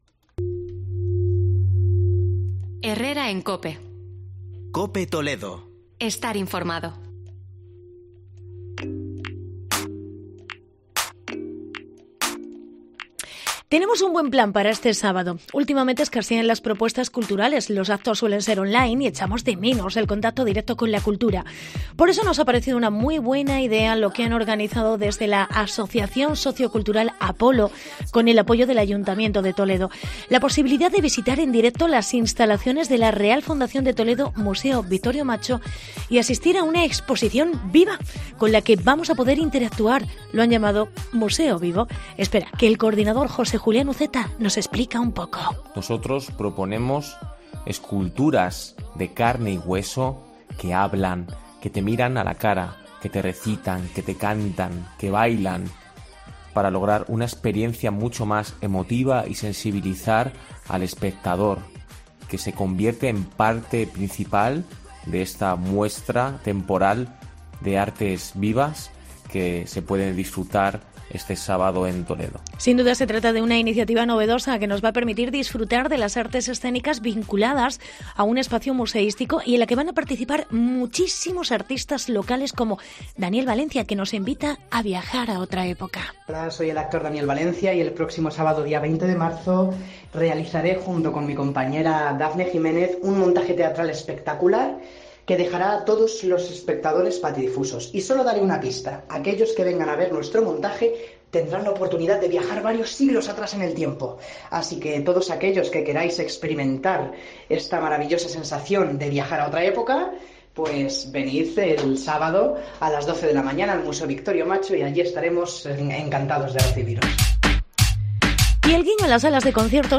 Reportaje Museo Vivo